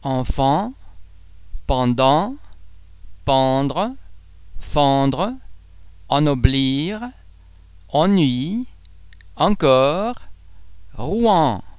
en em an am vowel-base similar to ong in (pong)
·[en]
en_enfant.mp3